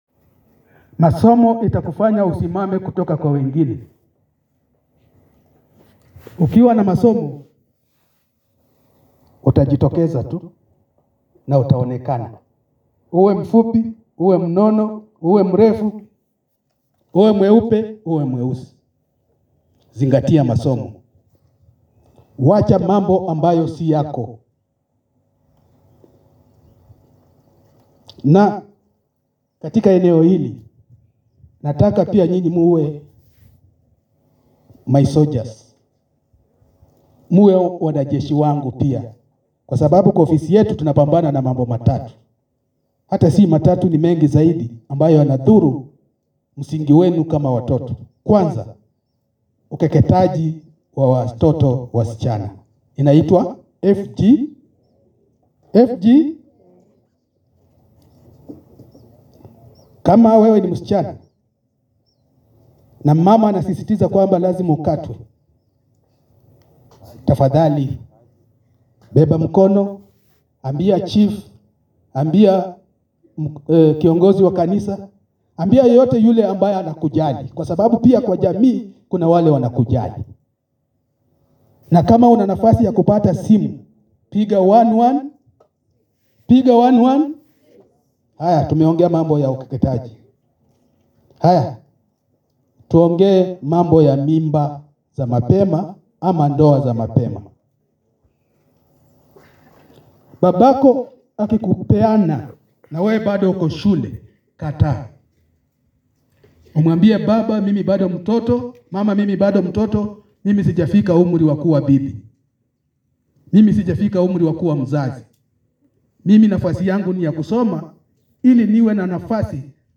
Akizungumza eneo la Lemek Narok magharibi katika hafla ya  madhimisho ya mtoto wa kifrika yaliyoandaliwa jana,kamishana huyo amesema elimu pekee ndio ufunguo wa maisha na kuwataka wenyeji wa eneo hilo kujiepusha na  mila potovu hasa ukeketaji mimba za mapema na ndoa za mapema ambazo zimepelekea idadi ya juu ya wasichana kukosa elimu.
Dcc-Narok-West-Derusis-Muyesu.mp3